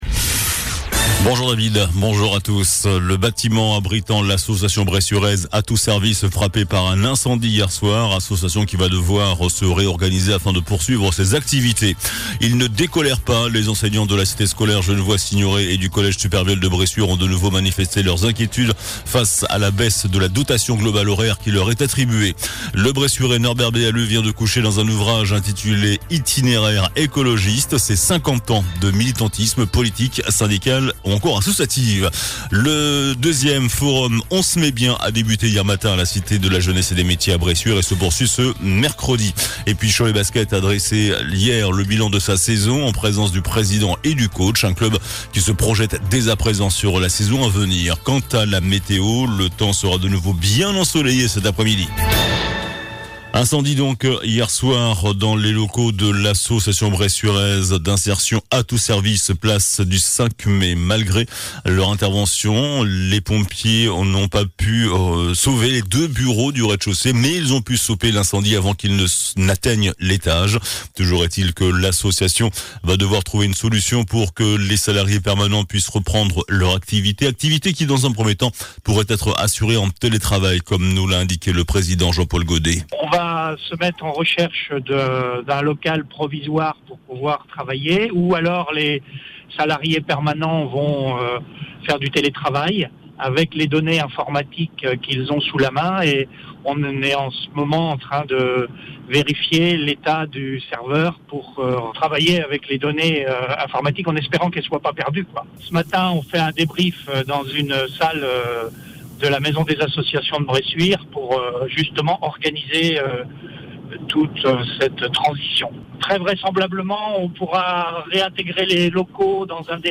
JOURNAL DU MERCREDI 31 MAI ( MIDI )